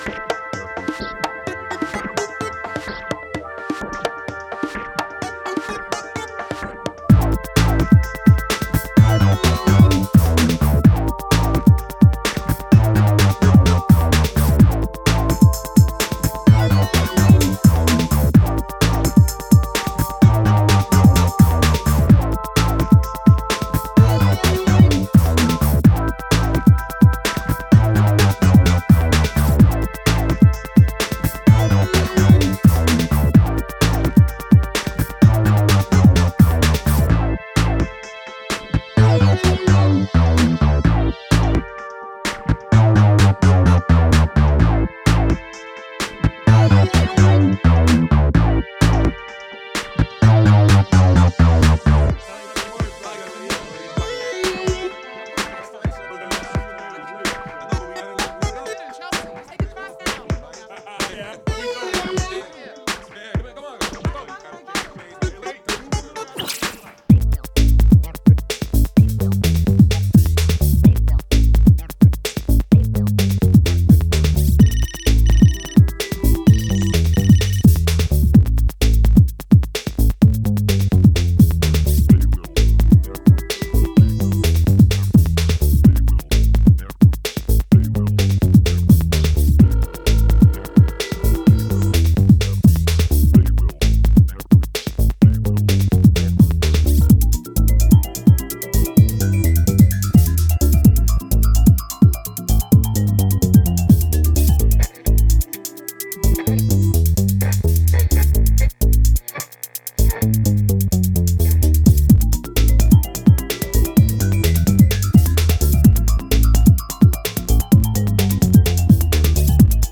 keep the energy rolling with a serious bass-heavy groover